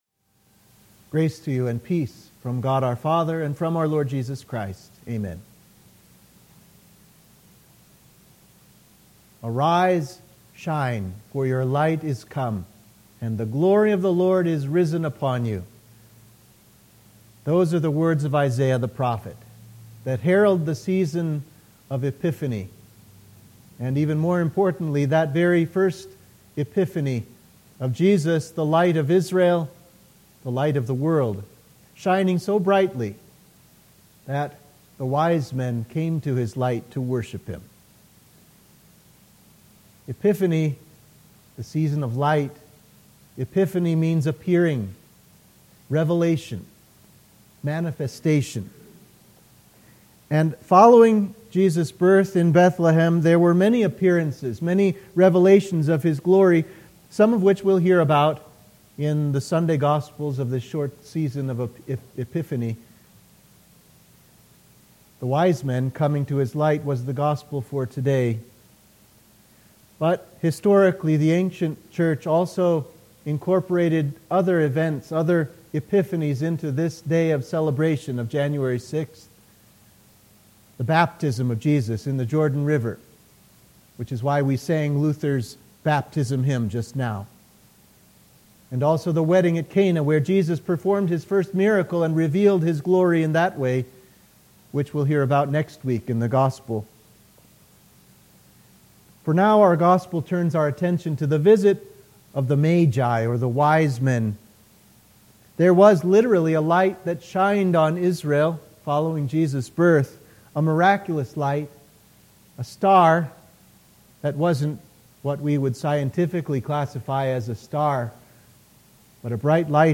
Sermon for the Epiphany of Our Lord